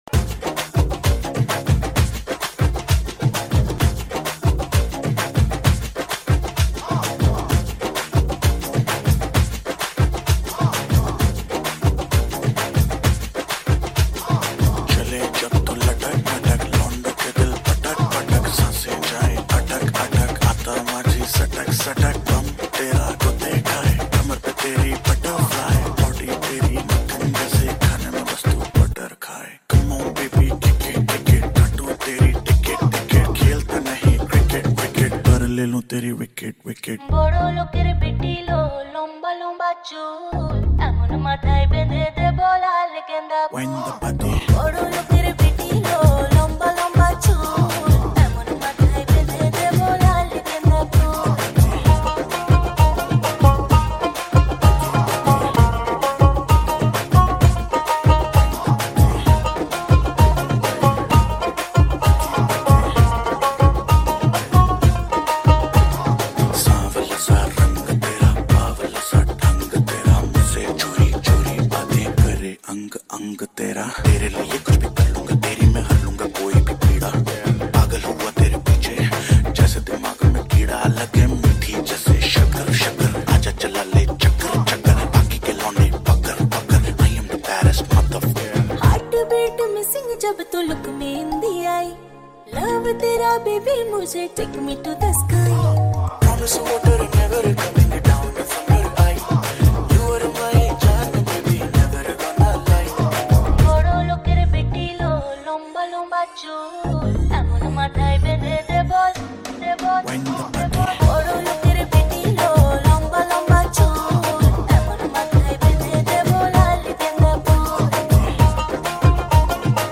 Sinhala Remix New Song